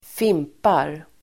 Uttal: [²f'im:par]